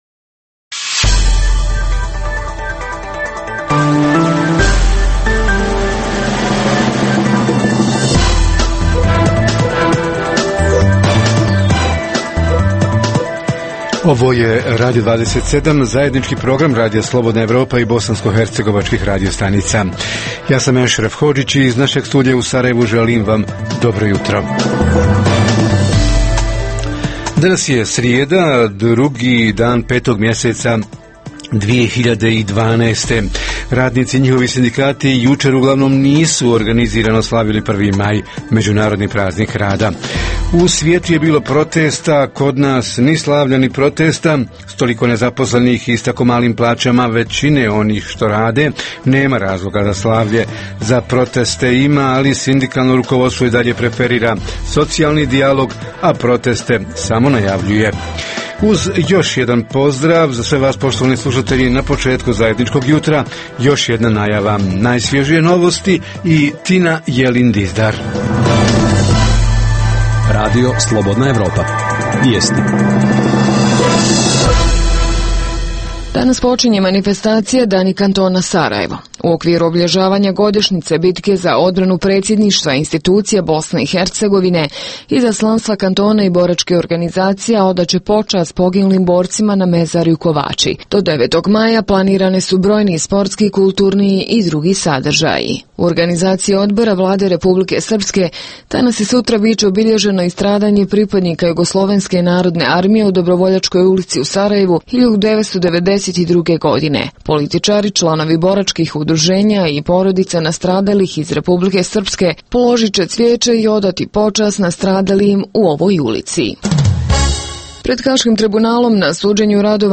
Tema jutra: S radnicima na prvomajskom uranku – zašto se u BiH tako malo proizvodi, zašto je toliko nezaposlenih - kako do više radnih mjesta, do veće proizvodnje, većih plaća i boljeg životnog standard? Reporteri iz cijele BiH javljaju o najaktuelnijim događajima u njihovim sredinama.